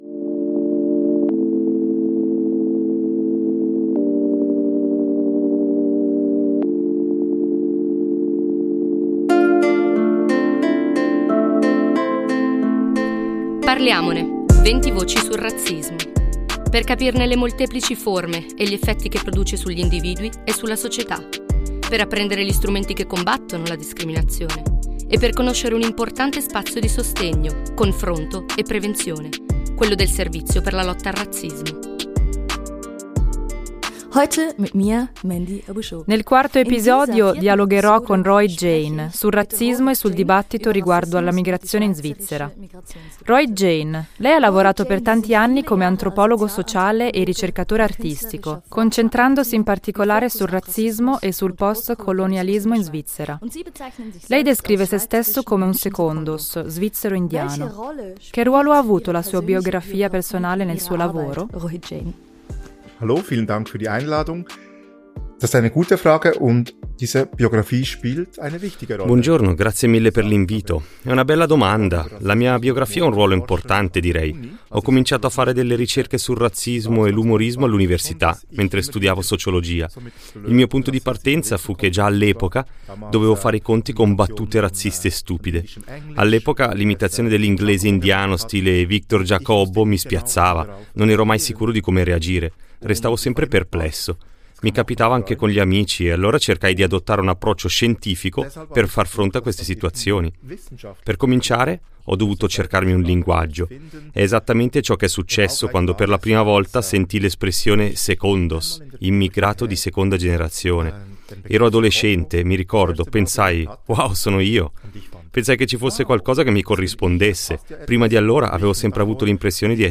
un'intervista